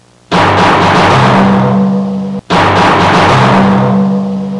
Big Gong Intro Sound Effect
Download a high-quality big gong intro sound effect.
big-gong-intro.mp3